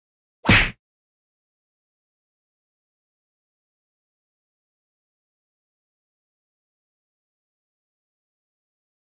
• Category: Cartoon sounds